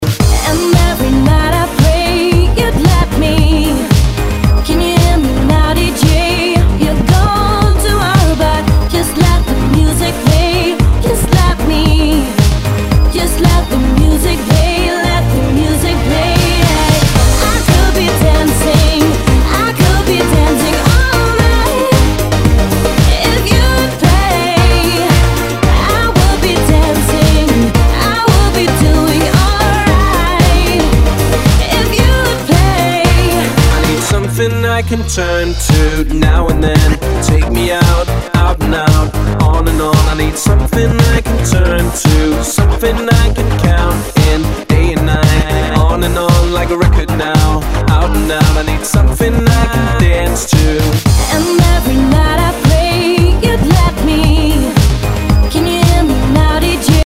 HOUSE/TECHNO/ELECTRO
ナイス！ヴォーカル・ハウス / シンセ・ポップ！